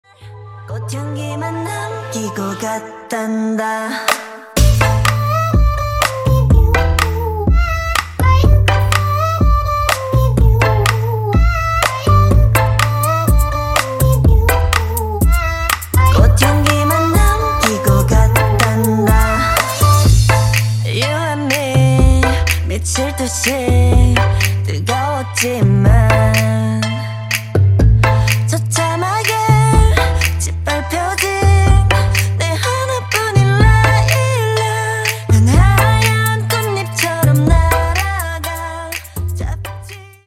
KPop ,Pop